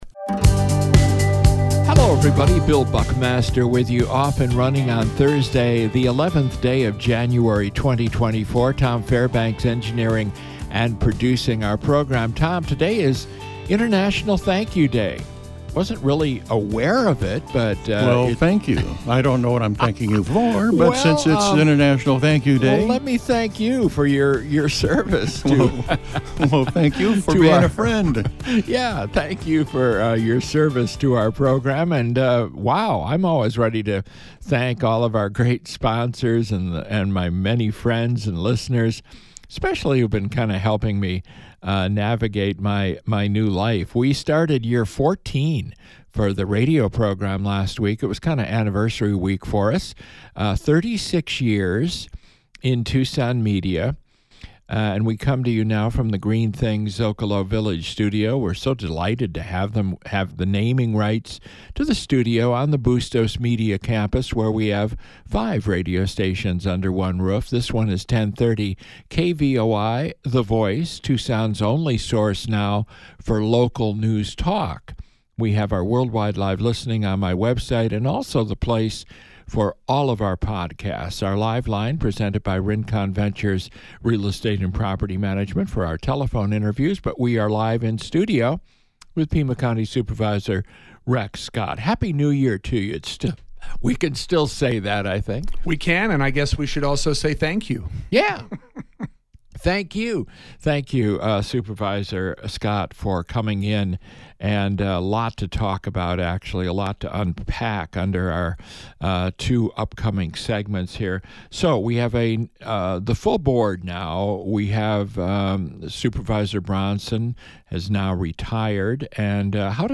Guests include Pima County Supervisor Rex Scott and former Arizona Health Director Will Humble.